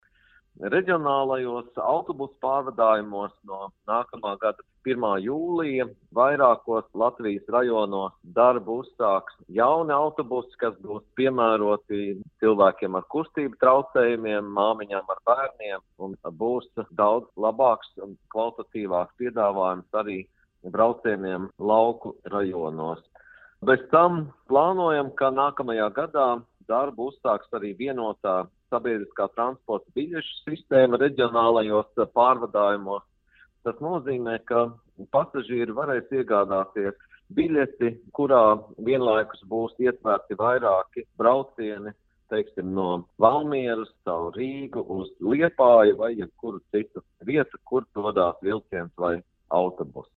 Patīkamas pārmaiņas šogad gaidāmas arī reģionālo autobusu pasažieriem. Par tām stāsta Satiksmes ministrs Tālis Linkaits: